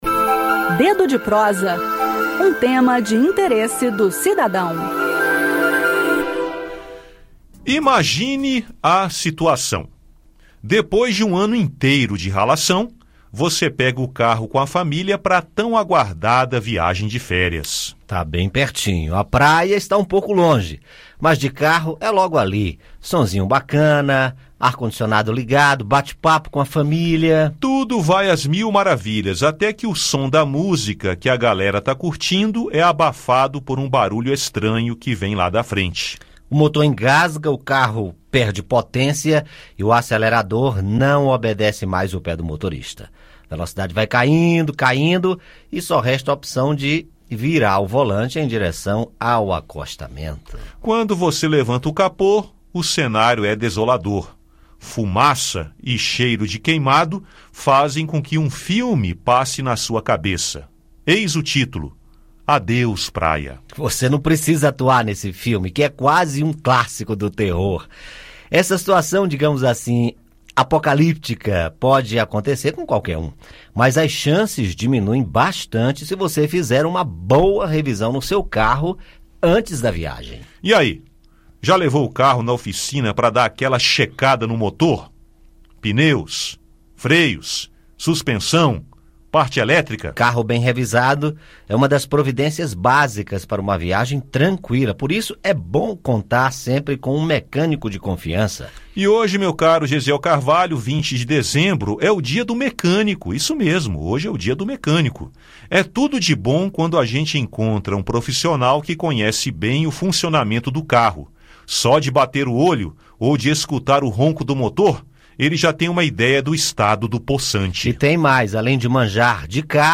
O Conexão Senado presta homenagem a esses profissionais no quadro Dedo de Prosa. Ouça o áudio com o bate papo sobre essa categoria profissional.